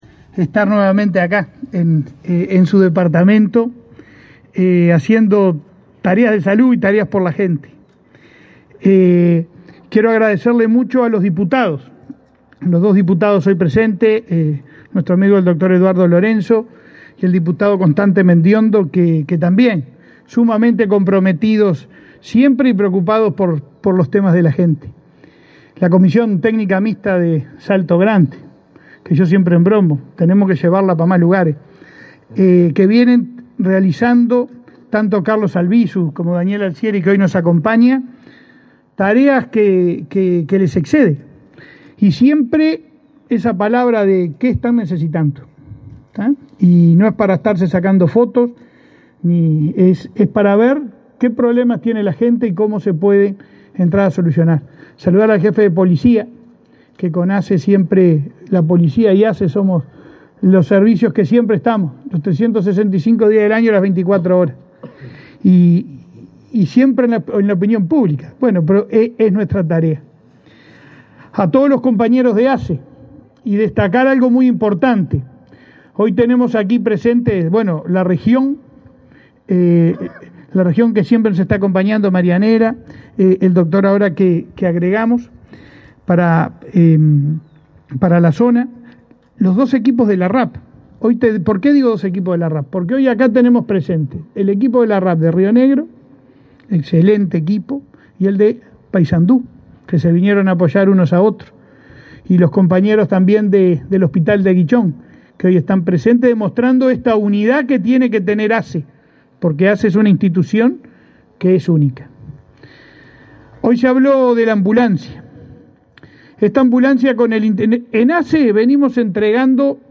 Conferencia de prensa por la entrega de ambulancia a la localidad de Algorta
Conferencia de prensa por la entrega de ambulancia a la localidad de Algorta 26/09/2022 Compartir Facebook X Copiar enlace WhatsApp LinkedIn La Comisión Técnica Mixta de Salto Grande y la Intendencia de Río Negro donaron a la Administración de los Servicios de Salud del Estado (ASSE) una ambulancia que será utilizada en la policlínica de la localidad de Algorta. Participaron del evento, este 26 de setiembre, el presidente de ASSE, Leonardo Cipriani, y el representante de Uruguay en la comisión, Carlos Albisu.